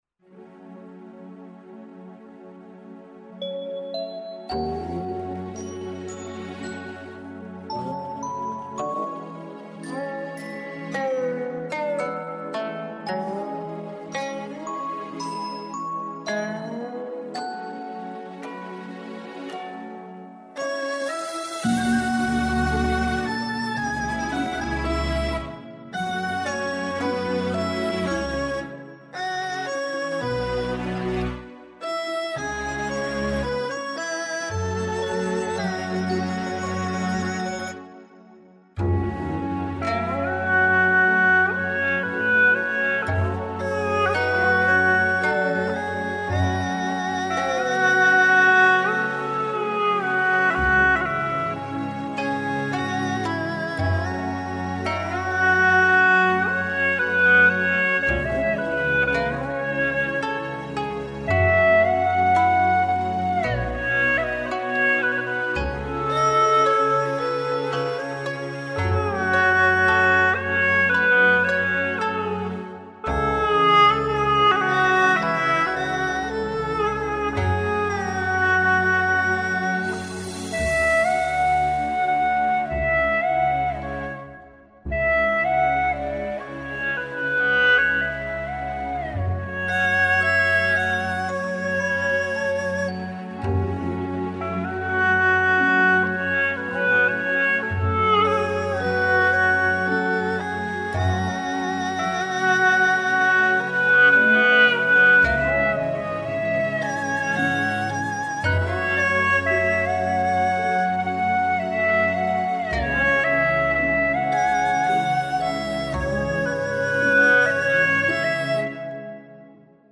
曲类 : 独奏
原本是超7孔作品，我在原作基础上做了些修改，用普通7孔完成。